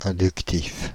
Ääntäminen
Ääntäminen Paris: IPA: [ɛ̃.dyk.tif] Tuntematon aksentti: IPA: /ɛ̃.dyk.tiv/ Haettu sana löytyi näillä lähdekielillä: ranska Käännös Konteksti Adjektiivit 1. inductive logiikka Suku: m .